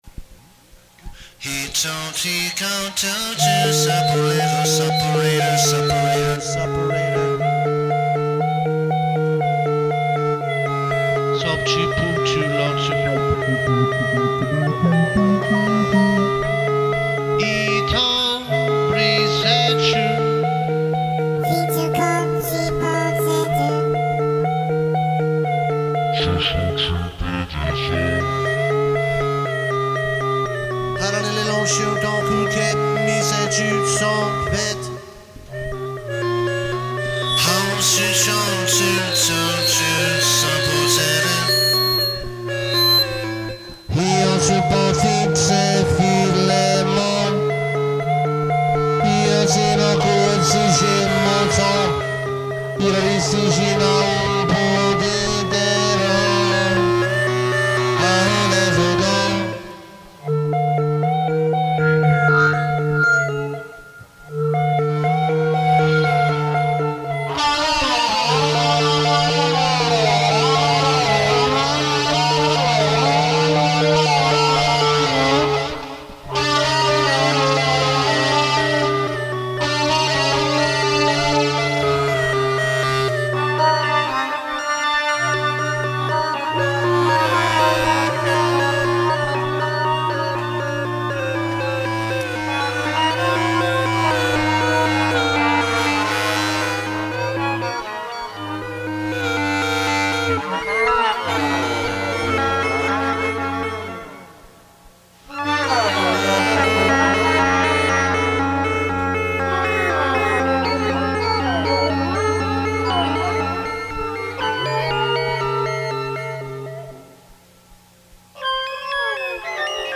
Recorded live at home in Manhattan.
alto clarinet, alto clarinet mouthpiece, vocals, fx
vocals, percussion, peck horn, fx
Stereo